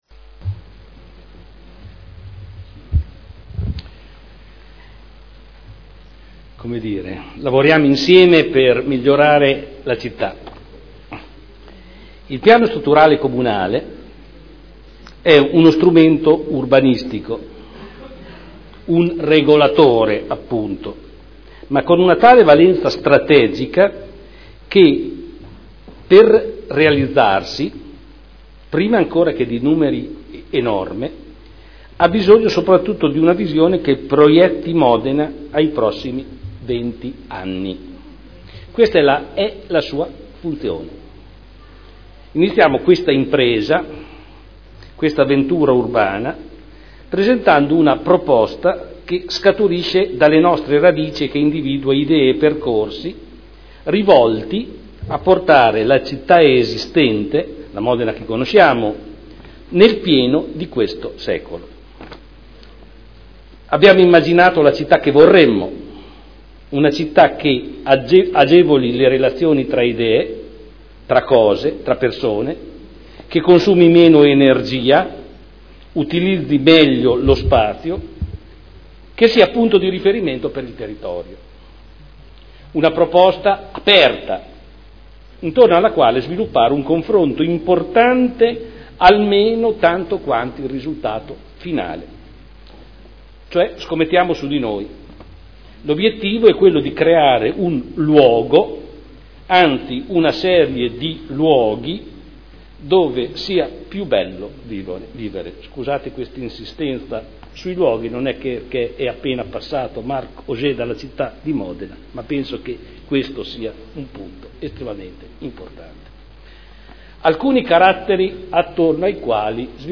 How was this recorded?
Seduta del 01/10/2012. Comunicazione sul PSC.